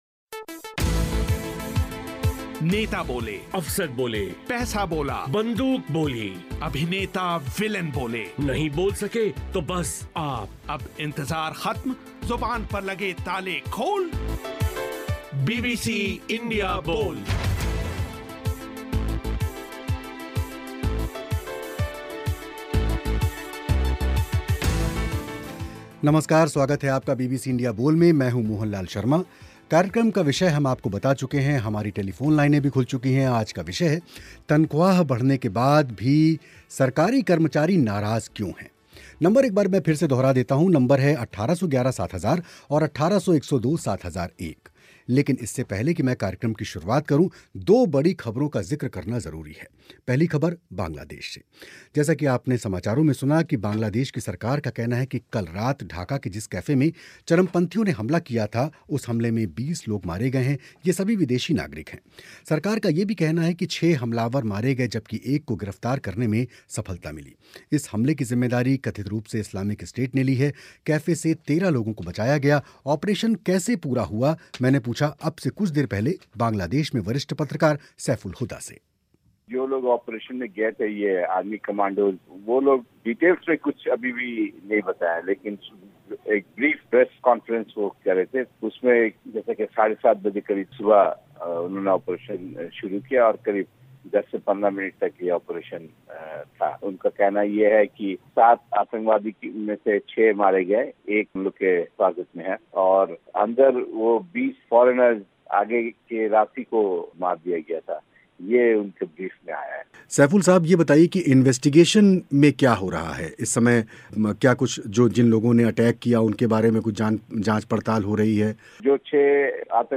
तनख्वाह बढ़ने के बावजूद केंद्र सरकार के कर्मचारी नाराज़ क्यों सातवें वेतन आयोग की सिफारिशों के खिलाफ़ देश भर के लाखों कर्मचारी 11 जुलाई से हड़ताल पर क्या सरकारी कर्मचारियों के जले पर नमक छिड़का गया है या उनकी नाराज़गी बेबुनियाद है. बीबीसी इंडिया बोल में इसी विषय पर हुई चर्चा